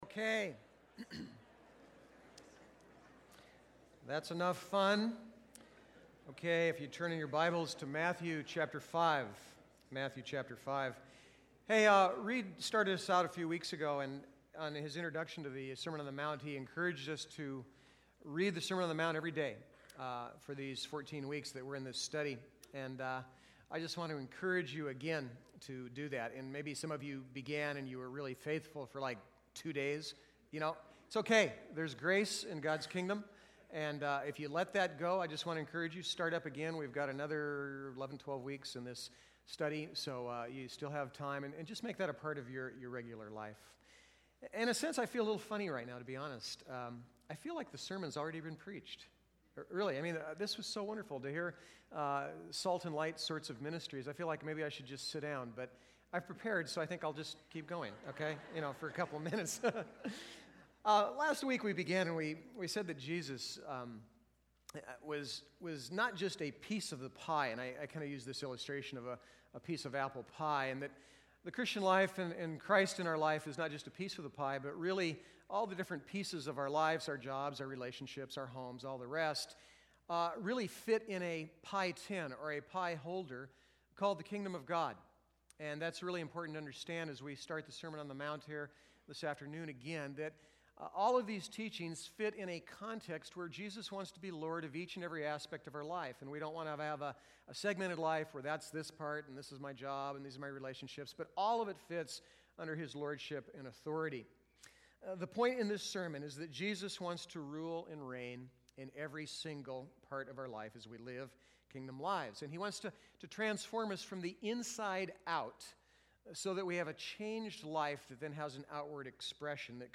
Sermon on the Mount Service Type: Sunday « The Beatitudes